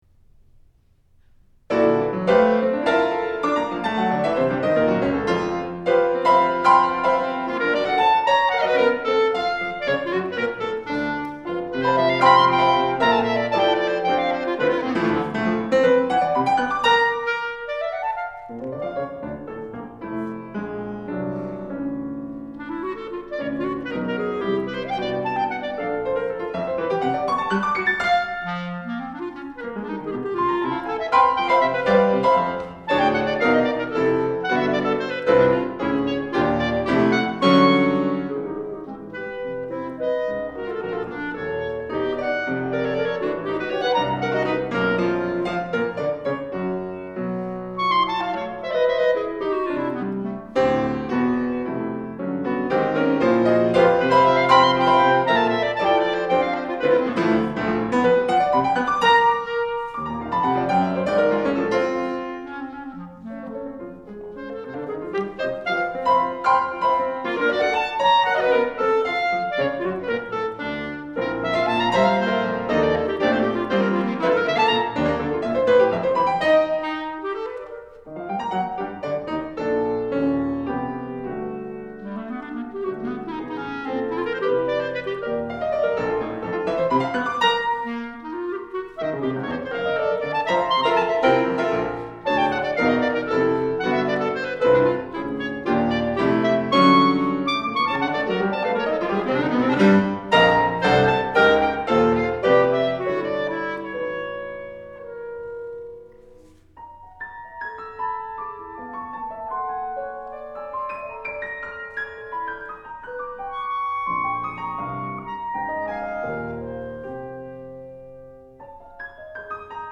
Voicing: Clarinet Solo